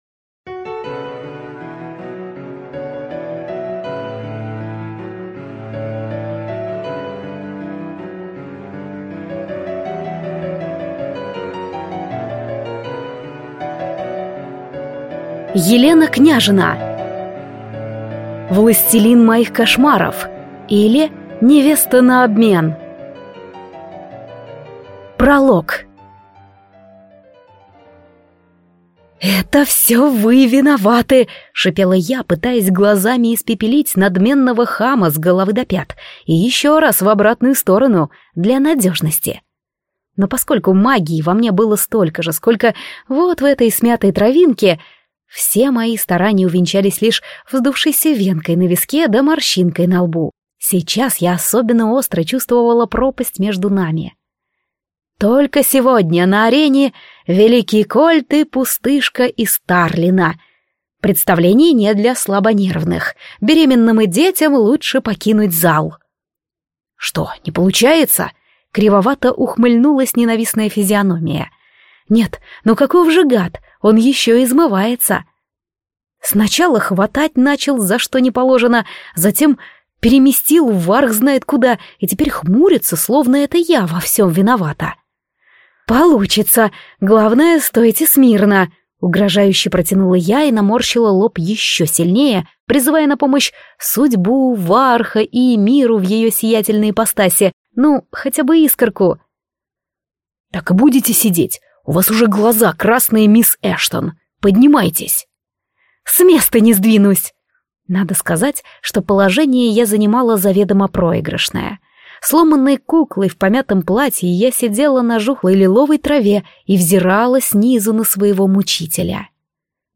Аудиокнига Властелин моих кошмаров, или Невеста на обмен | Библиотека аудиокниг